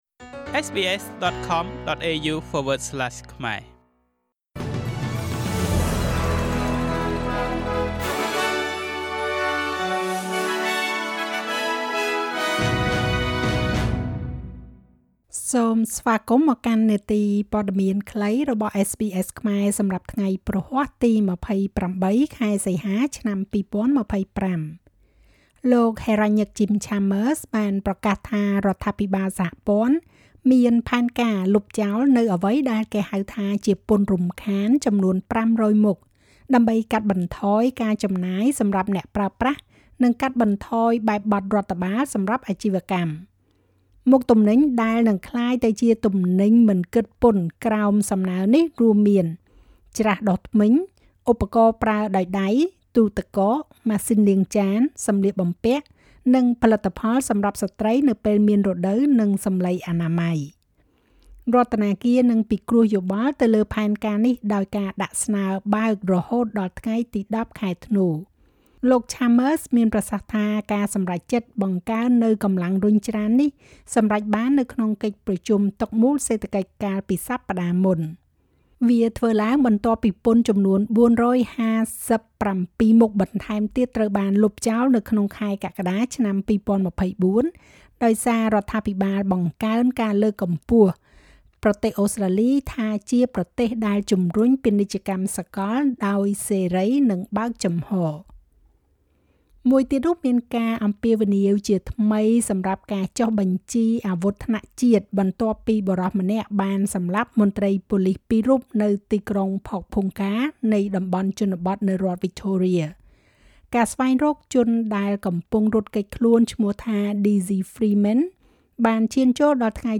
នាទីព័ត៌មានខ្លីរបស់SBSខ្មែរ សម្រាប់ថ្ងៃព្រហស្បតិ៍ ទី២៨ ខែសីហា ឆ្នាំ២០២៥